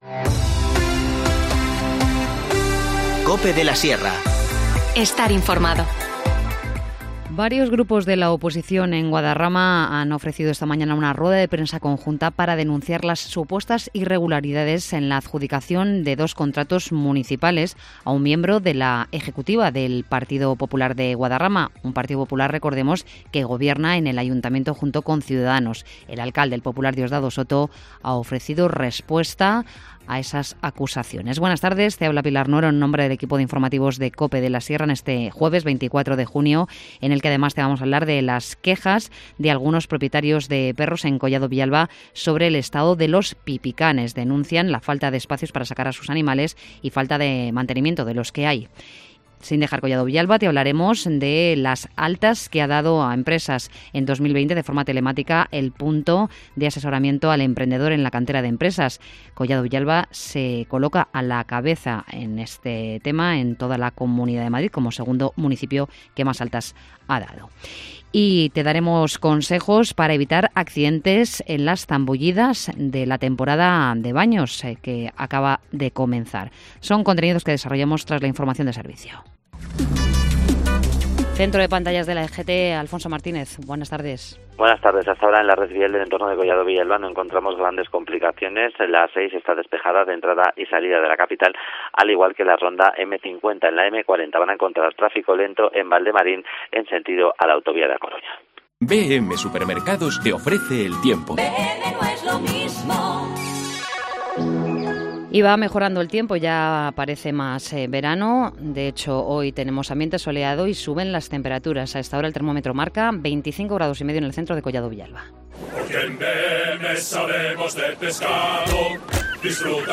Informativo Mediodía 24 junio